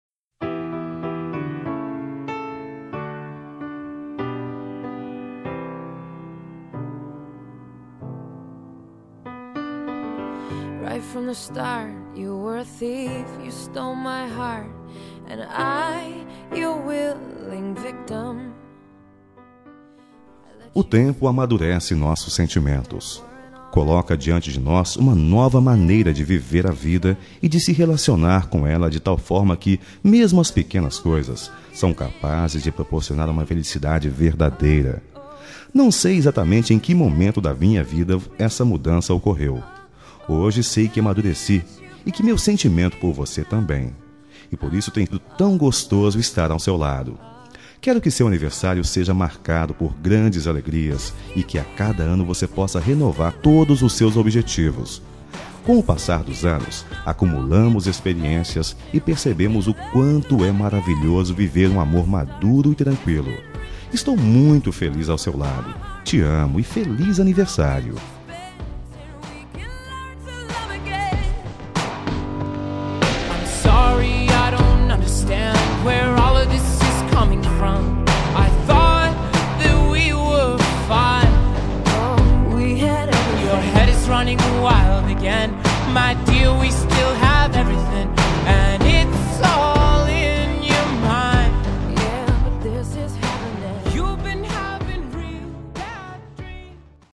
Voz Masculina
Código: 0023 – Música: Just Give Me a Reason – Artista: Pink / Nate Ruess